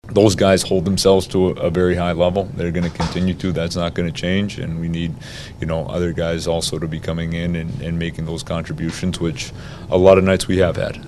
The coach says his veteran team leaders, Crosby, Malkin, and Kris Letang, are showing the way for their younger teammates.